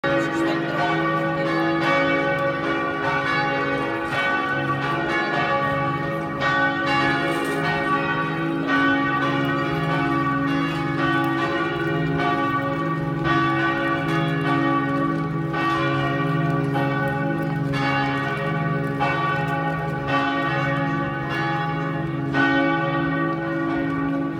大小の鐘の音、その反響。時を奏でる鐘の音がハモるように絡み合い、バラバラなのに、何故か絶妙に調和しています。
キラキラしながら重厚感もあり、哀愁もマイペース感も満載の鐘の音。
como-bells.m4a